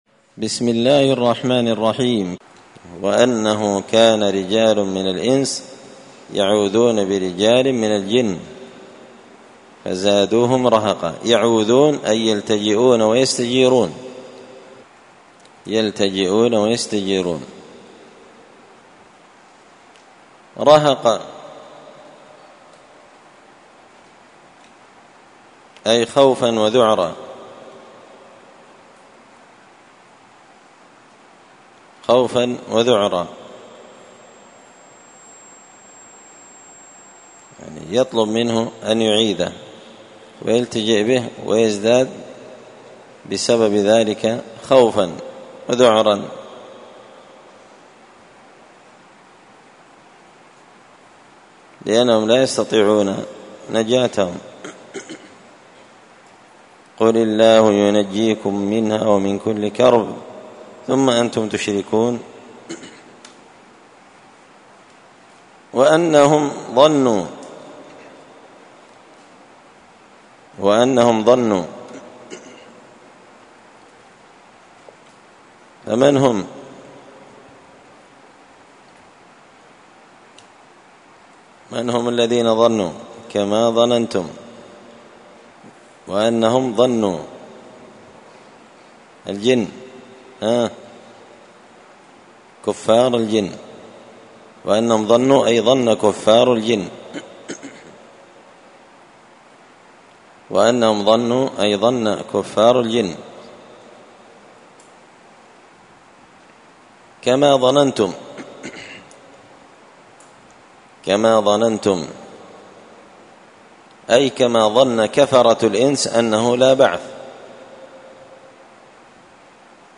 مسجد الفرقان قشن_المهرة_اليمن